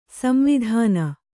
♪ samvidhāna